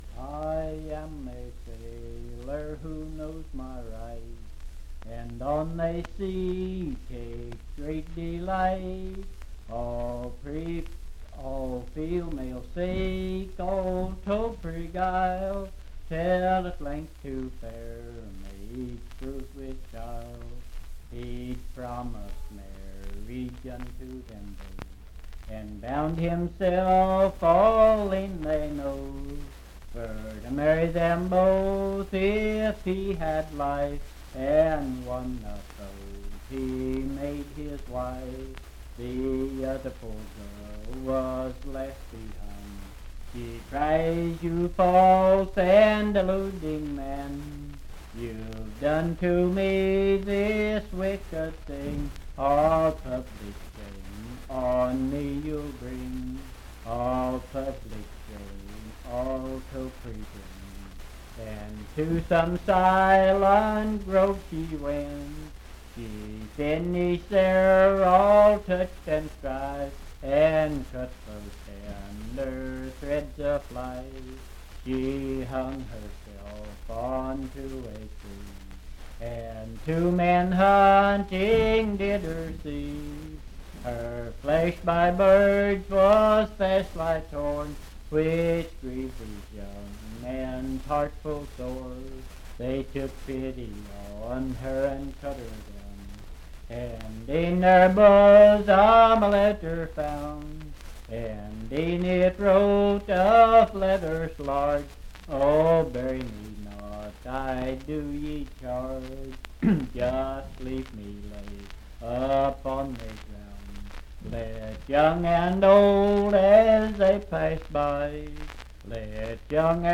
Unaccompanied vocal music
Verse-refrain 7(4).
Voice (sung)
Pendleton County (W. Va.)